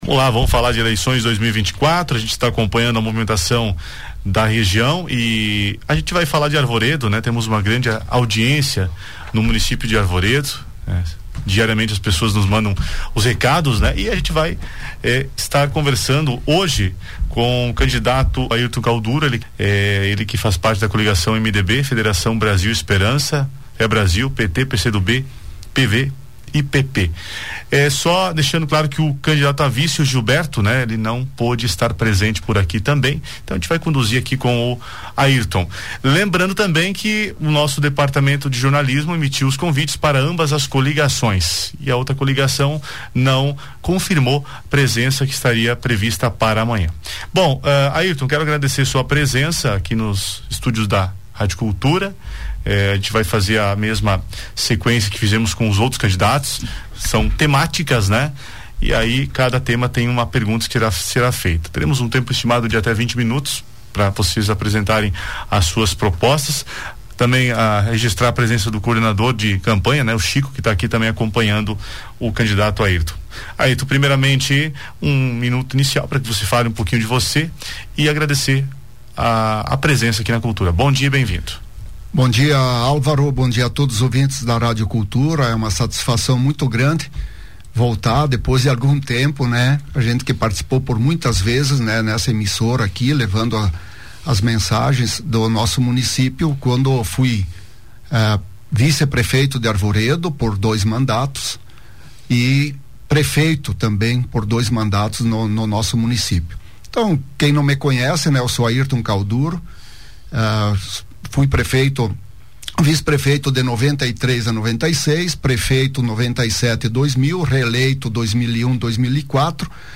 Ouça: Arvoredo Eleições 2024 - Radio Cultura Xaxim